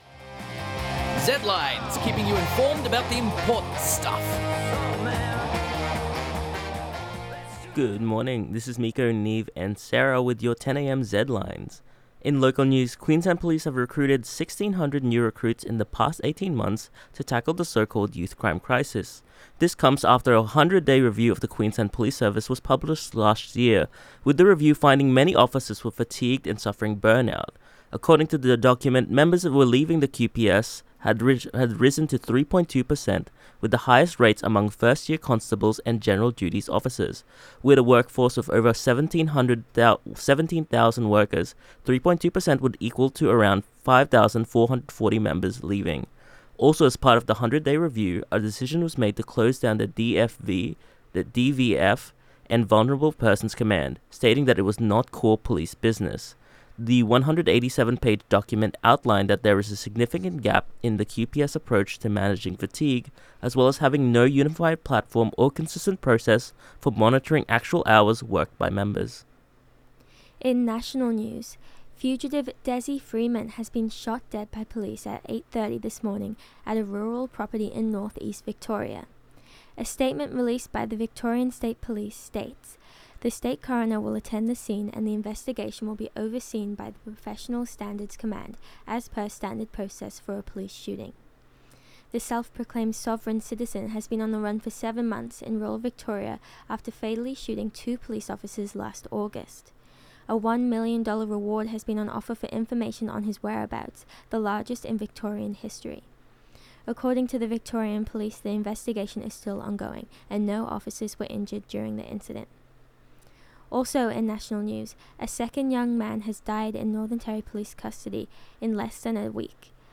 Zedlines Bulletin